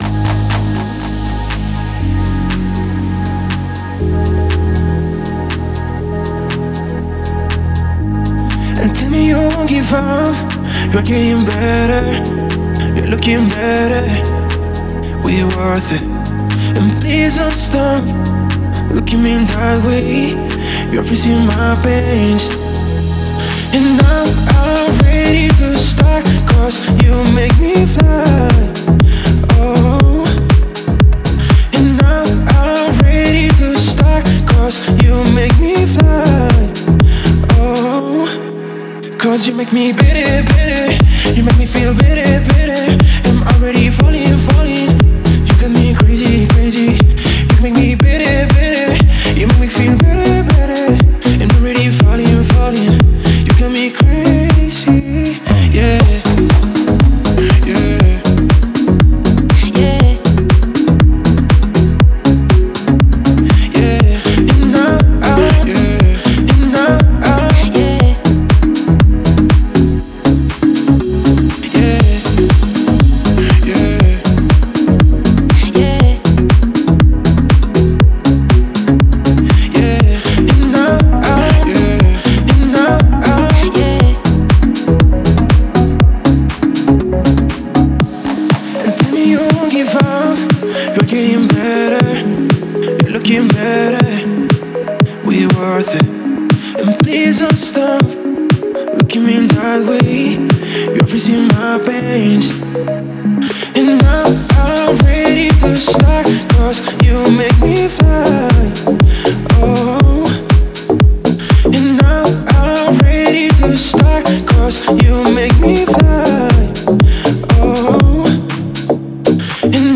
это трек в жанре поп с элементами электроники